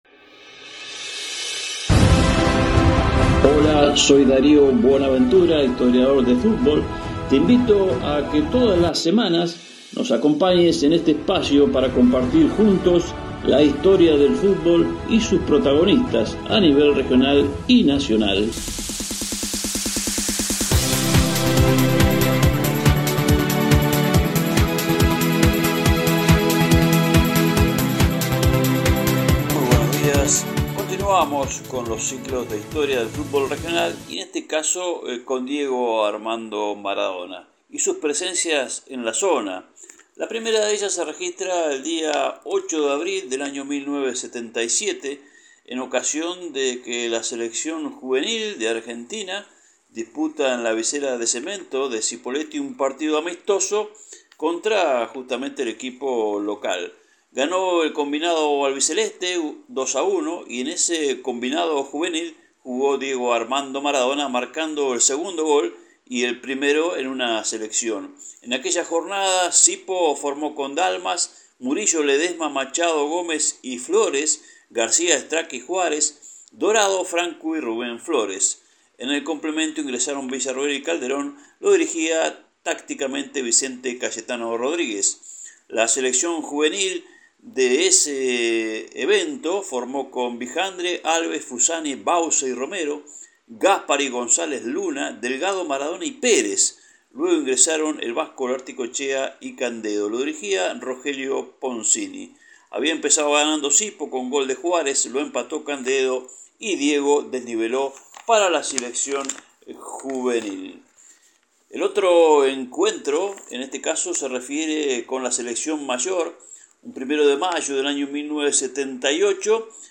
Esa historia escrita, ahora es narrada en “DIGO”, a través de su programa Historias del Fútbol que se estrena todos los viernes en la agenda regional de la APP de «Río Negro»